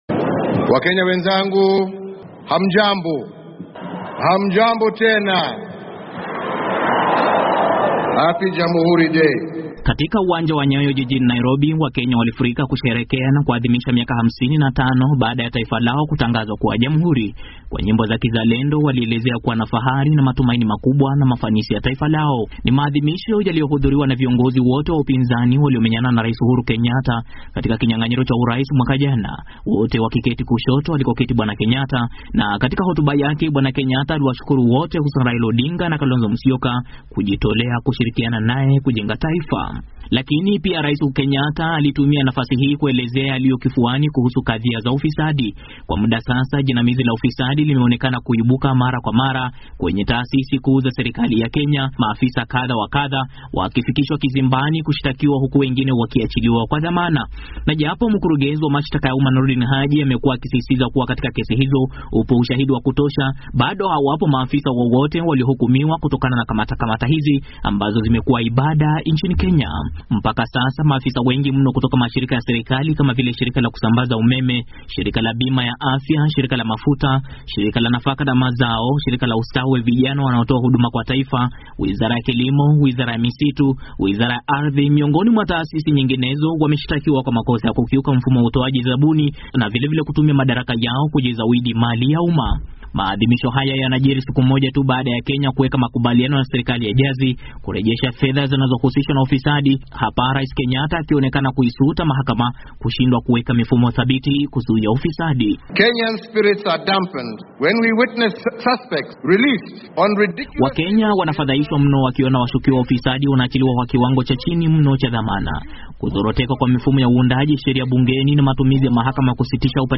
Katika uwanja wa michezo wa Nyayo jijini Nairobi, wakenya walifurika kusherehekea na kuadhimisha siku hiyo muhimu kwa nyimbo za kizalendo walielezea kuwa na fahari na matumaini makubwa ya mafanikio ya taifa lao.